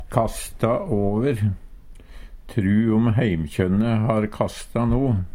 kasta over - Numedalsmål (en-US)